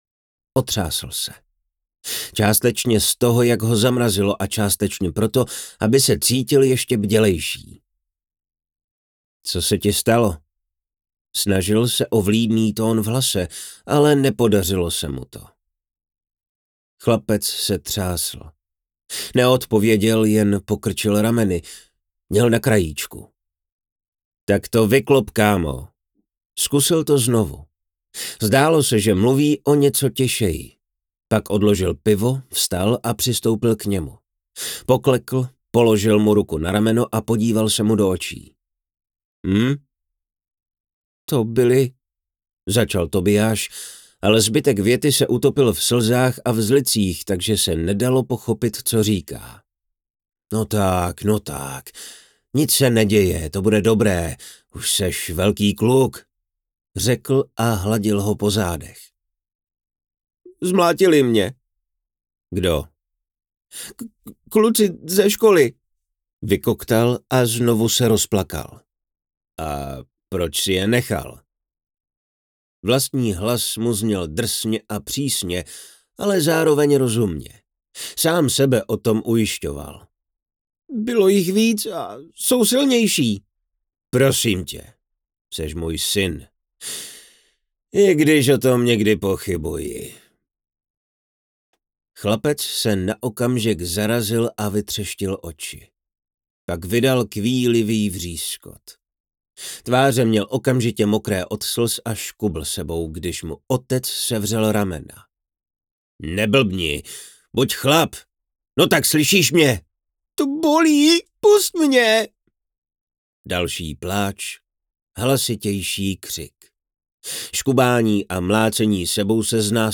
Audiokniha Kronika smrti je společným dílem čtrnácti autorů z Česka a Slovenska. Tato velká mezinárodní spolupráce vyústila do mrazivého sborníku příběhů, které vám dokážou, že Smrt je nemilosrdným bohem našeho světa.
trailer-Kronika-smrti.wav